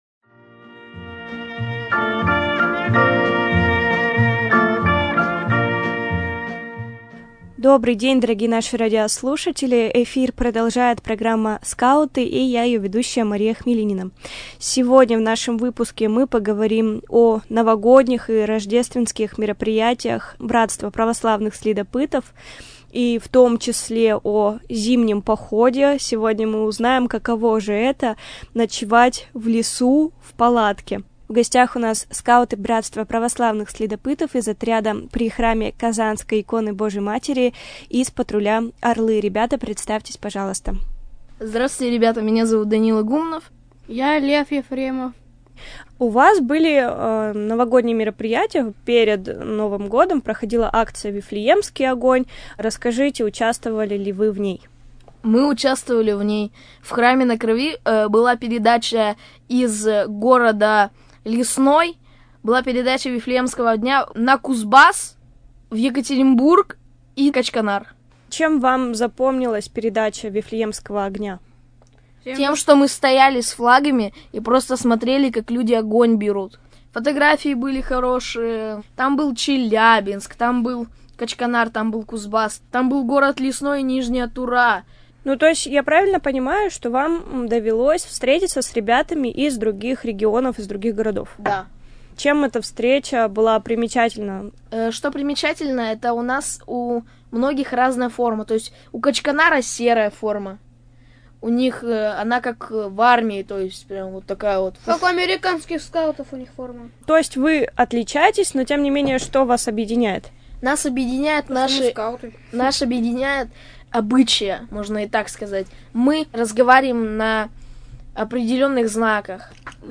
Беседа со скаутами из отряда при храме Казанской иконы Божией Матери Скачать файл | Копировать ссылку